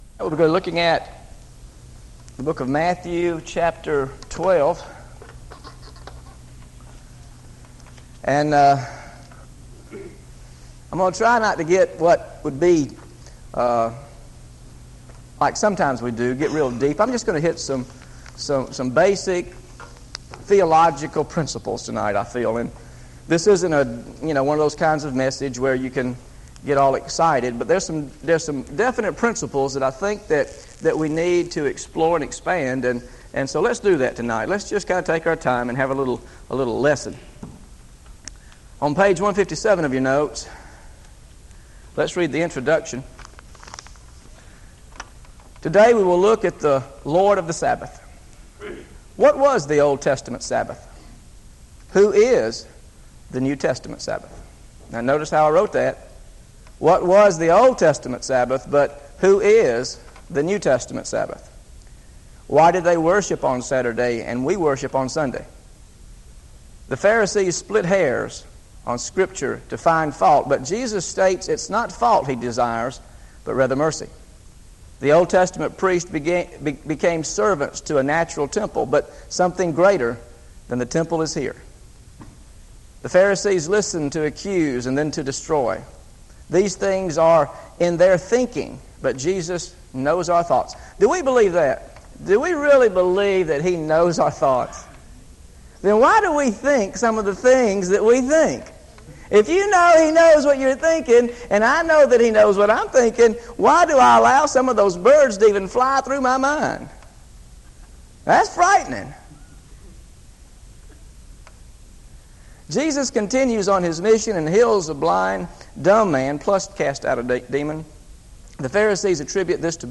GOSPEL OF MATTHEW BIBLE STUDY SERIES This study of Matthew: Matthew 12 Part 1 How to Discover Jesus as Lord of Sabbath is part of a verse-by-verse teaching series through the Gospel of Matthew.